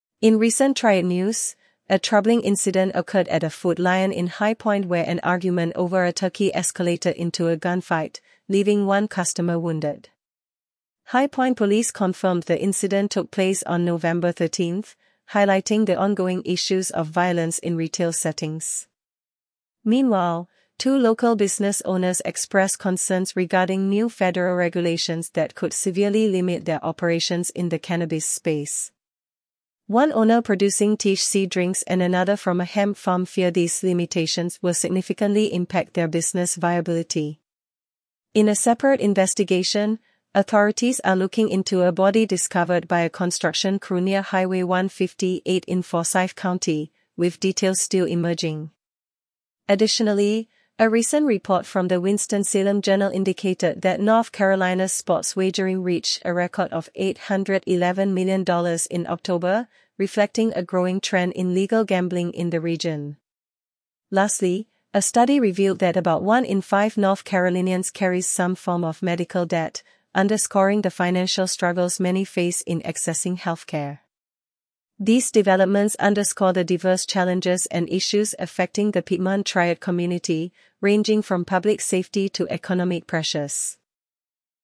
North Carolina News